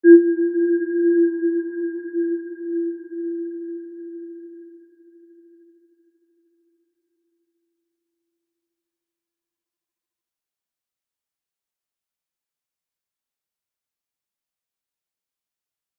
Aurora-G4-mf.wav